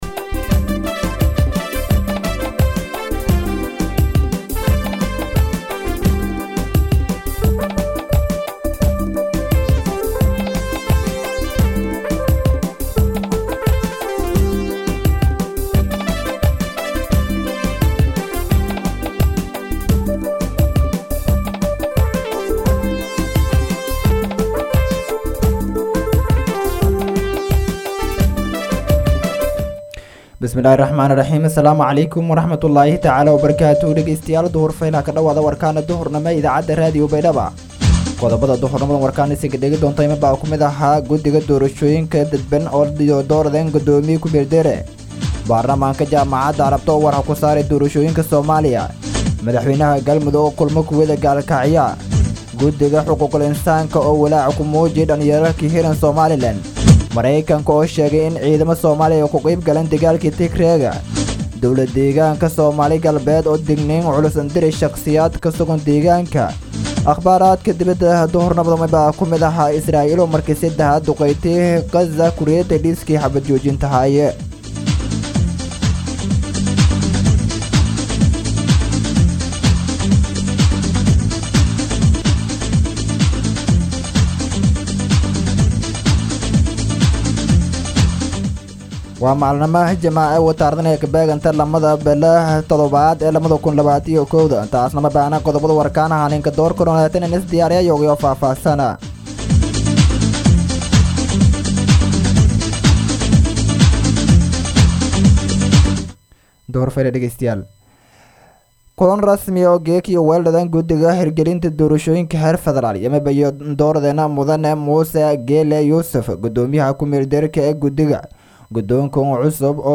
DHAGEYSO:- Warka Duhurnimo Radio Baidoa 2-7-2021